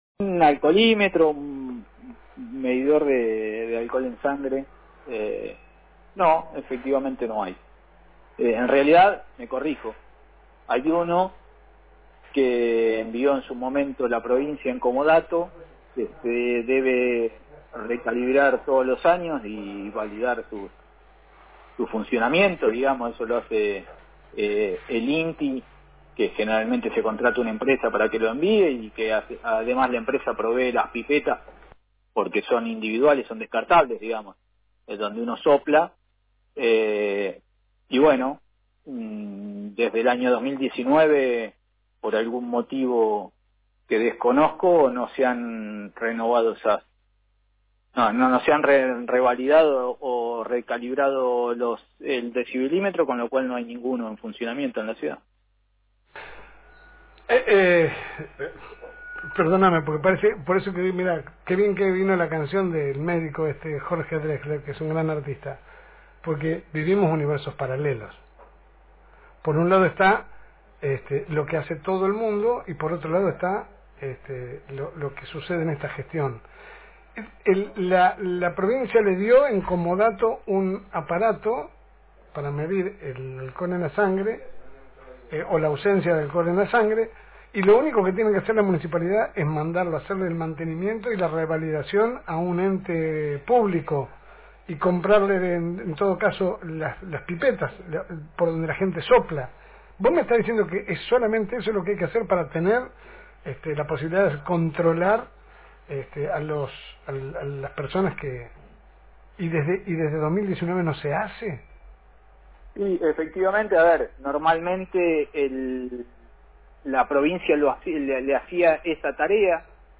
Compartimos la nota completa con el Juez de Faltas de Lobos Silvio Canosa.